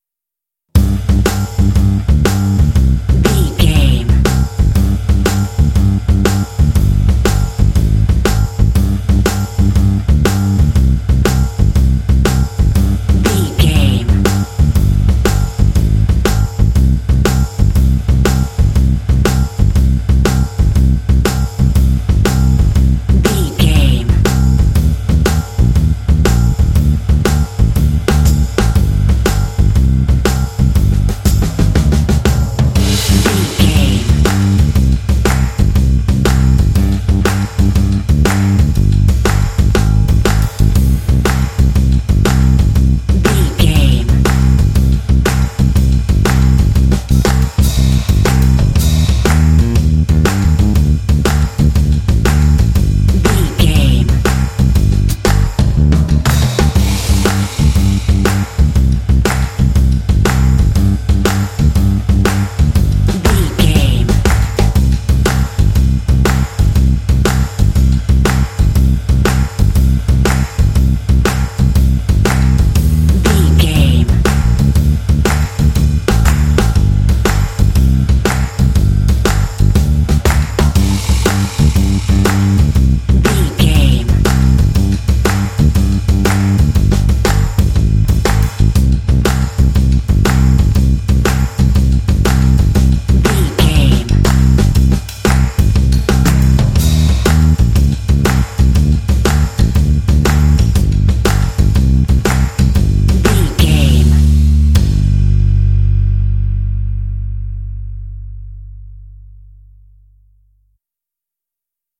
This 12-bar blues track
Aeolian/Minor
groovy
smooth
bass guitar
drums
blues
jazz